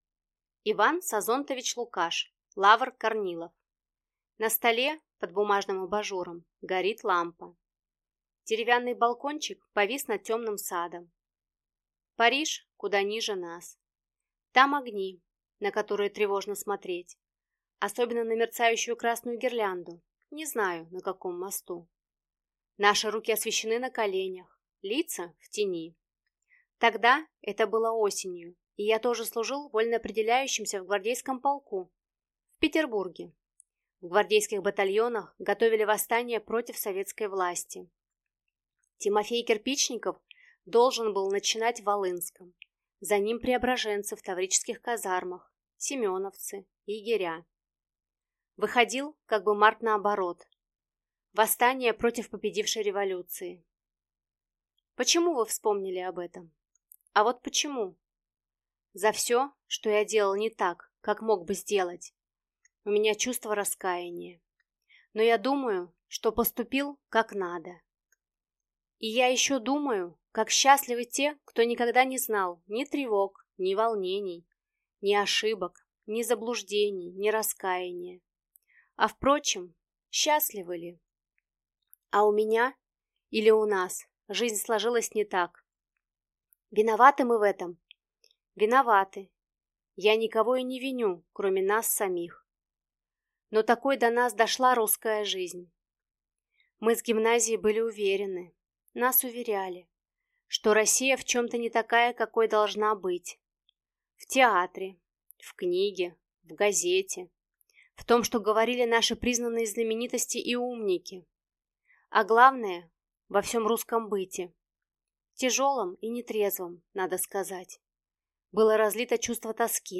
Аудиокнига Лавр Корнилов | Библиотека аудиокниг